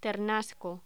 Locución: Ternasco
voz